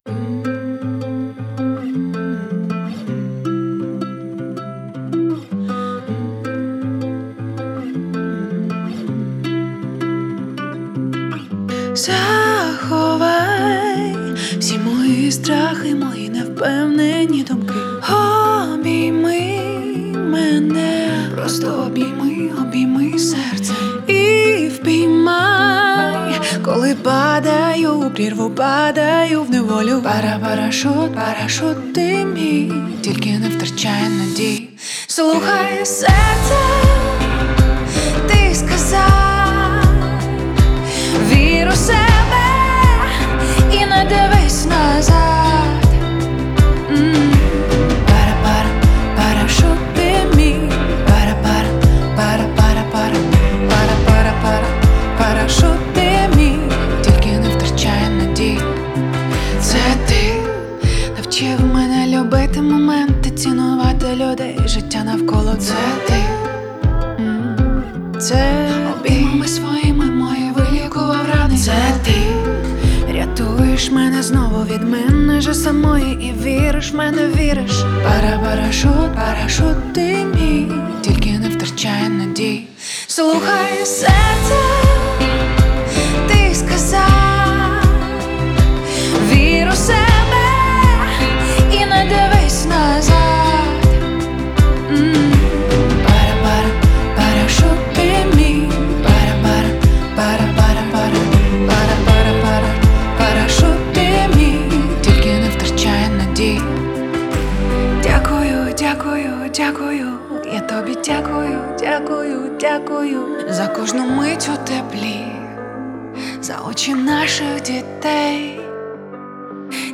это яркий поп-трек, наполненный энергией и позитивом.